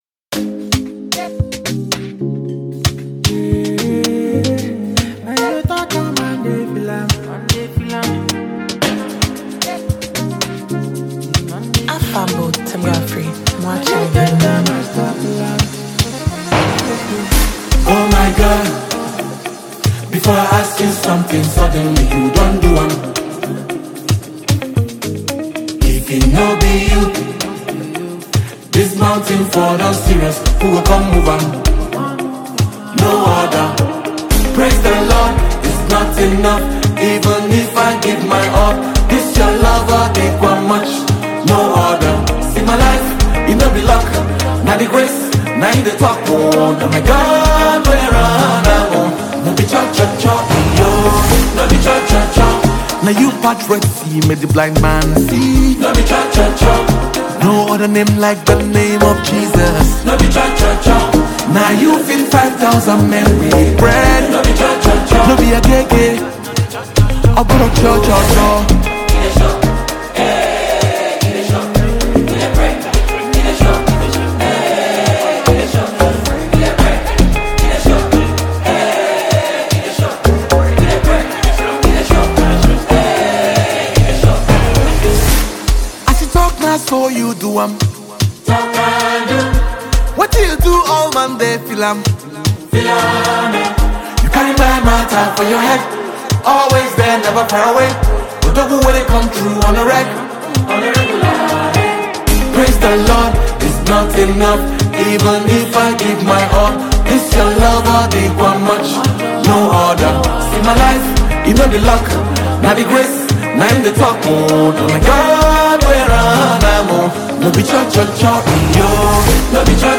gospel
blending contemporary gospel with Afrocentric melodies